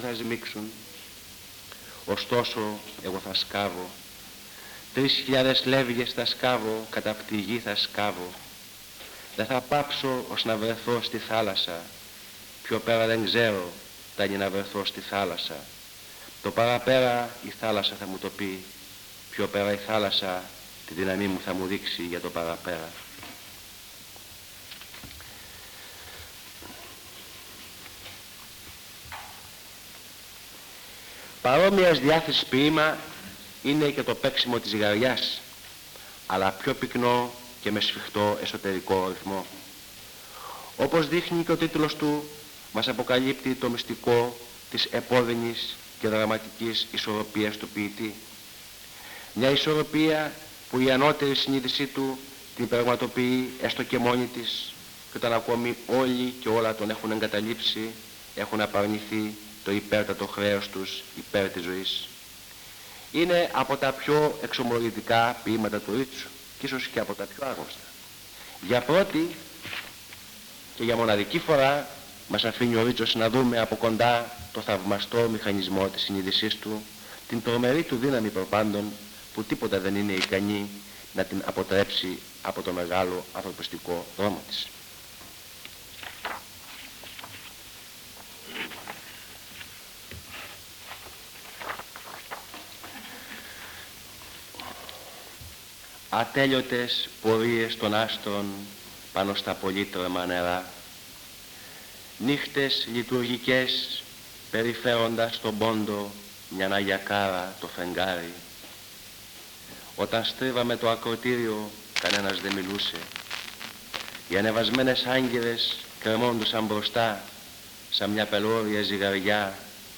Εξειδίκευση τύπου : Εκδήλωση
Περιγραφή: Κύκλος Μαθημάτων με γενικό Θέμα "Σύγχρονοι Νεοέλληνες Ποιητές"